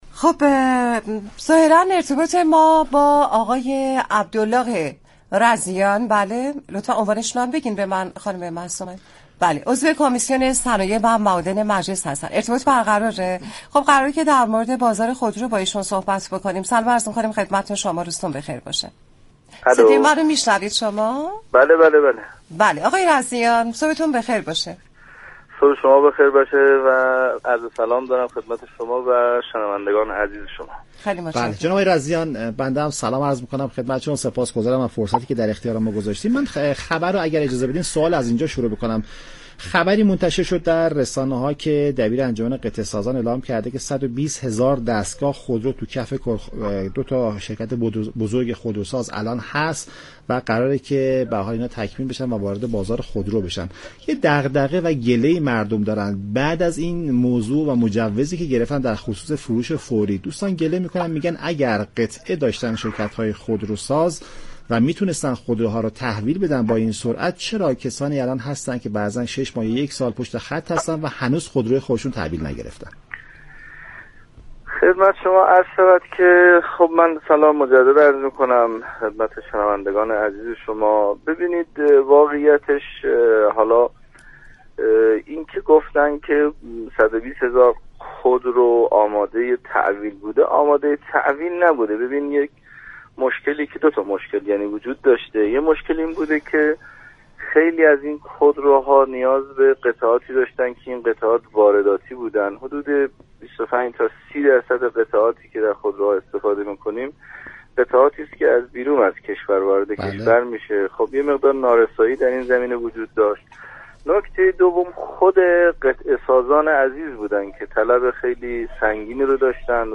عبدالله رضیان عضو كمیسیون صنایع و معادن مجلس در برنامه نمودار رادیو ایران گفت : قرار است تا آخر سال آینده تمام یك میلیون خودرو تحویل شود